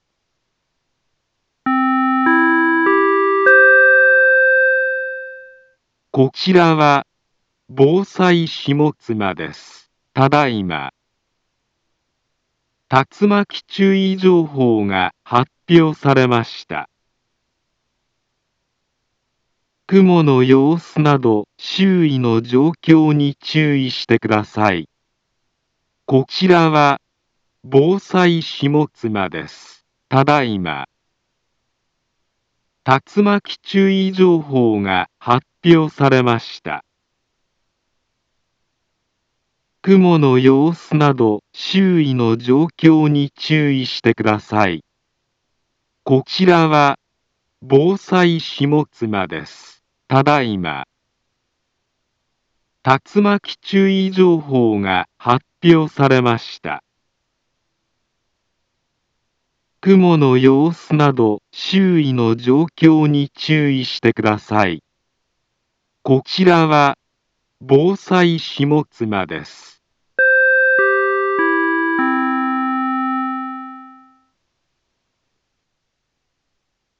Back Home Ｊアラート情報 音声放送 再生 災害情報 カテゴリ：J-ALERT 登録日時：2022-08-03 17:05:10 インフォメーション：茨城県北部、南部は、竜巻などの激しい突風が発生しやすい気象状況になっています。